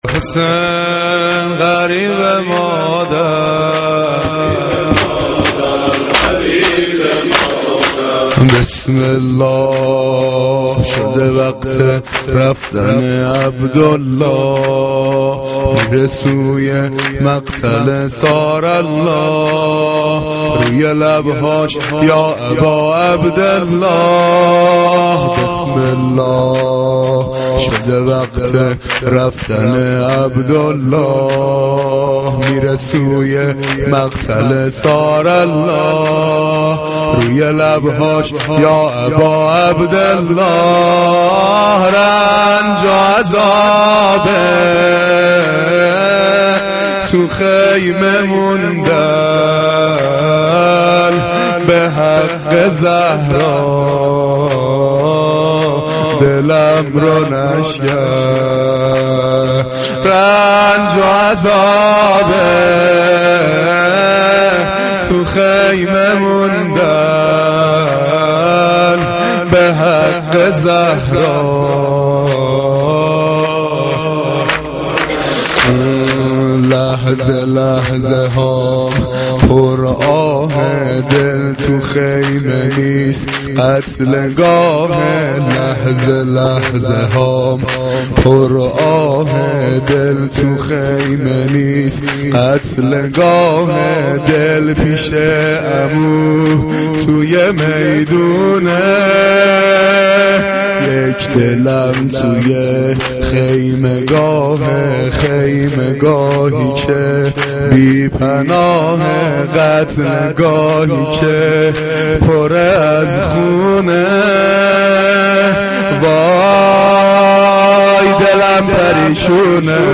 مداحی
Shab-5-Moharam-3.mp3